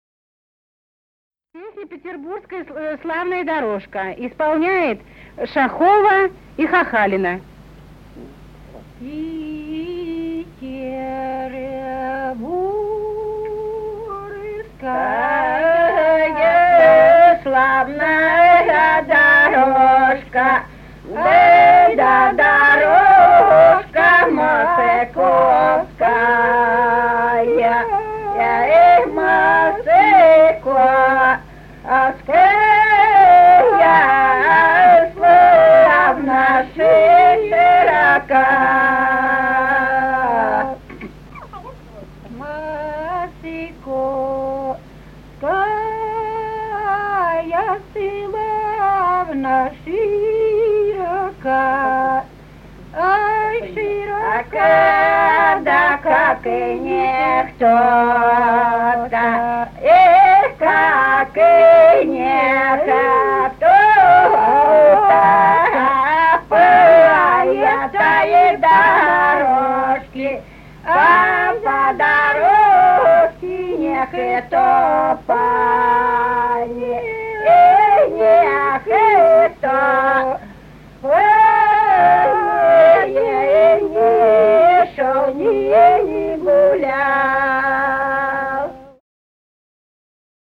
Русские народные песни Владимирской области [[Описание файла::2. Петербургская славна дорожка (рекрутская протяжная) стеклозавод «Красный Химик» Судогодского района Владимирской области.